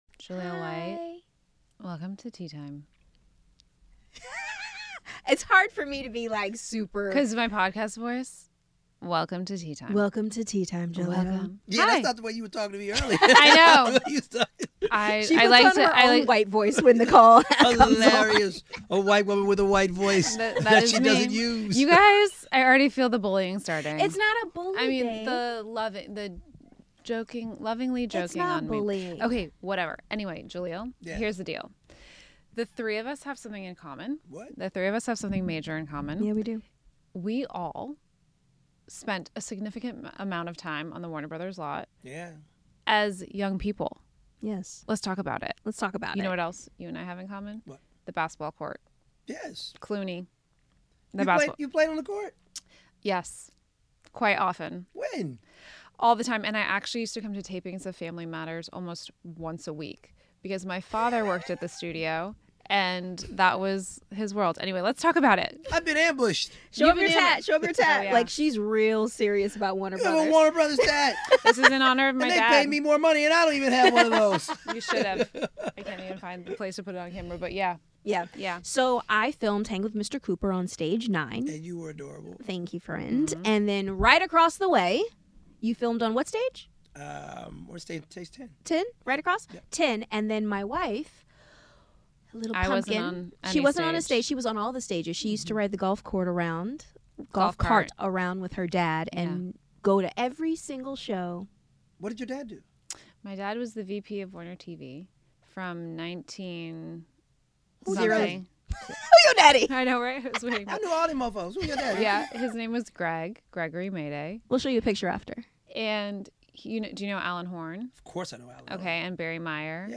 Jaleel White stops by! Join us for a wide-rranging conversation with our old friend.